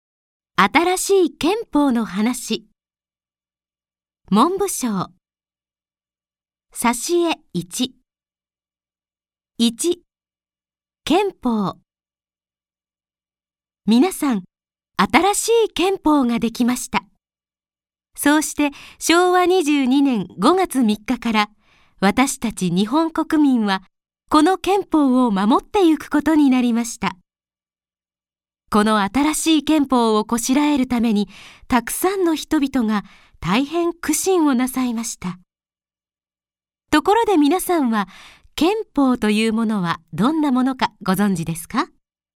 朗読ＣＤ　朗読街道147「あたらしい憲法のはなし」文部省